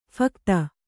♪ phakta